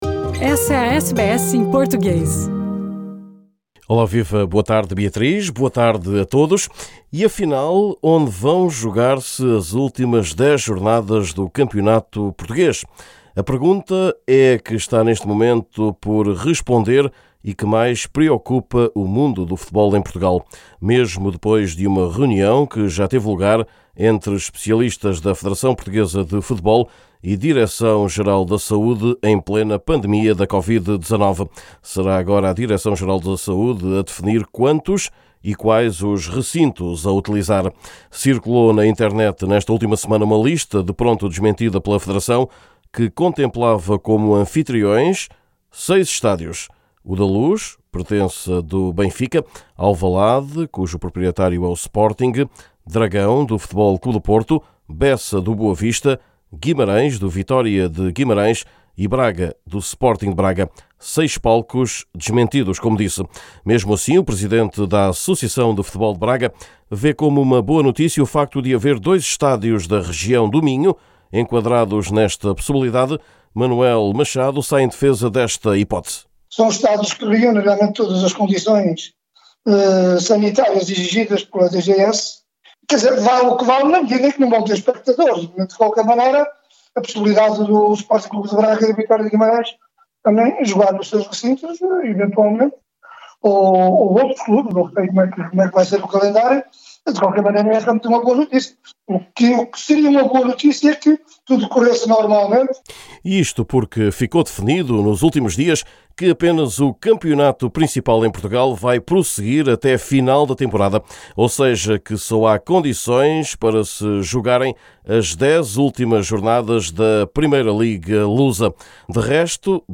Neste boletim semanal, também o futuro de dois treinadores lusos no estrangeiro.